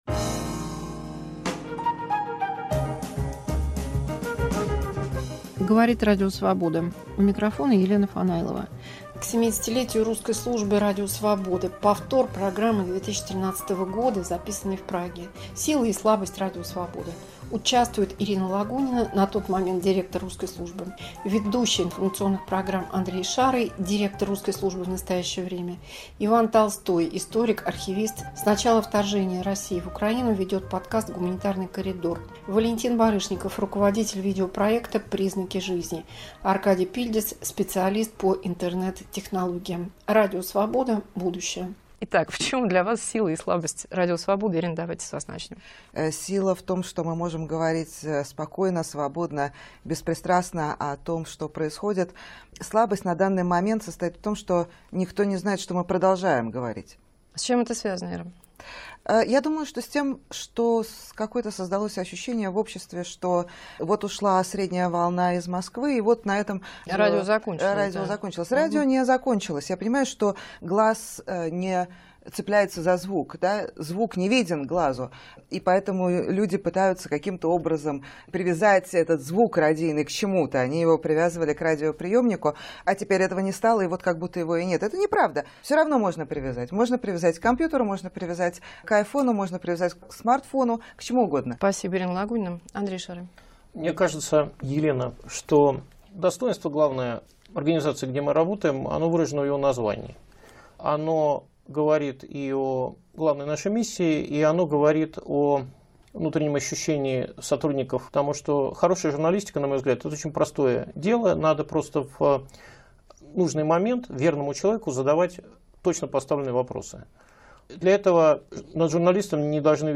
Архив 2013, студия в Праге